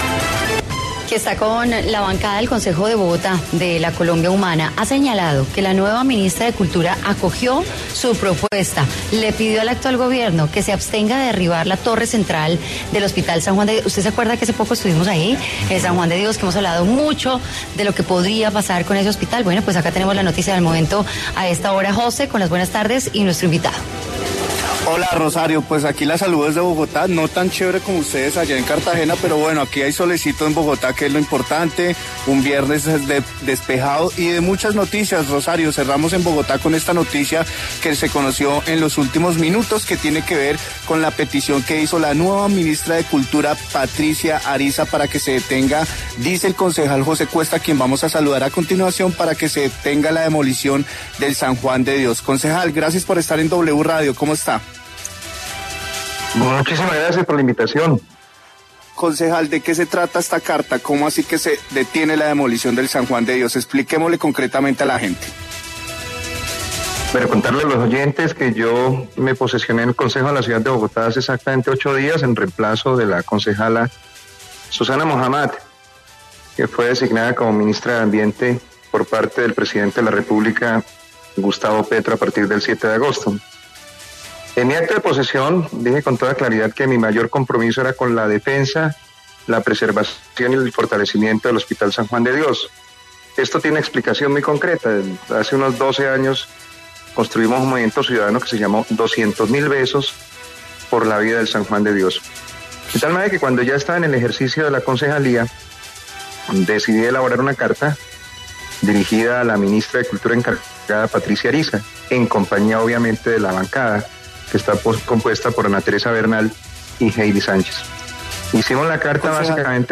El concejal José Cuesta, quien señala que la nueva MinCultura acogió su propuesta y le pidió a la ministra actual que se abstenga de derribar la Torre de San Juan de Dios en Bogotá habló a Contrarreloj.